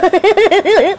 Goofy Laugh Sound Effect
Download a high-quality goofy laugh sound effect.
goofy-laugh-1.mp3